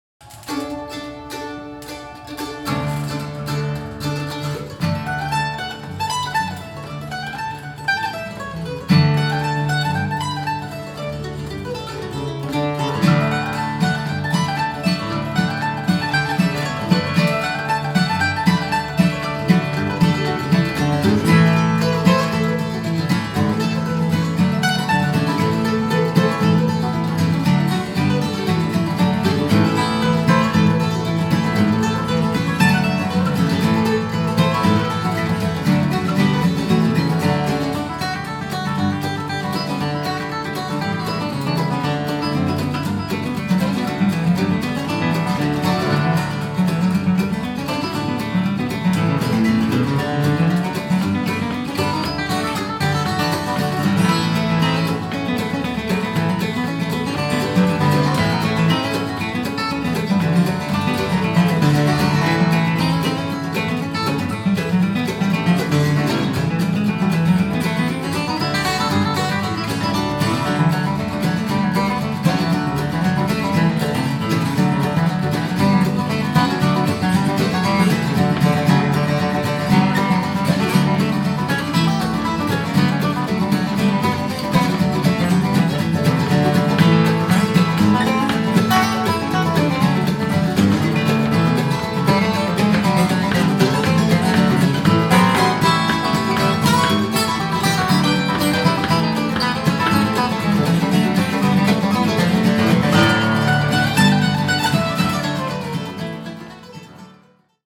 FORKED DEER | GUITAR
Forked_Deer_Gtr.mp3